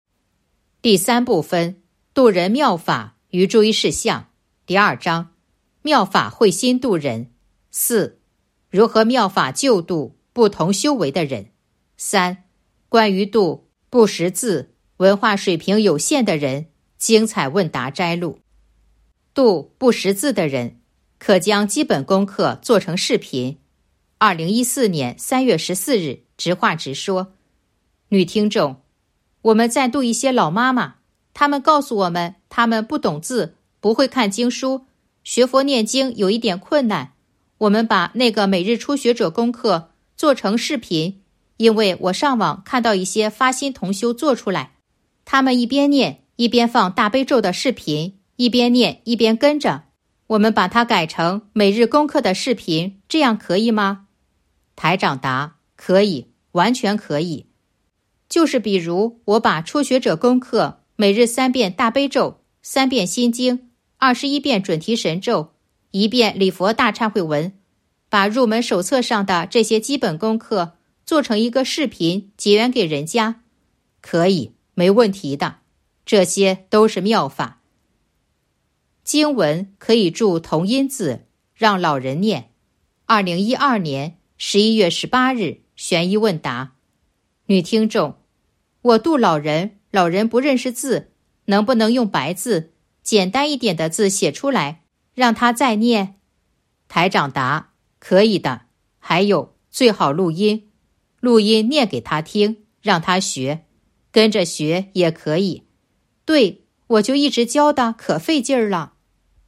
028.（三）关于度不识字、文化水平有限的人精彩问答摘录《弘法度人手册》【有声书】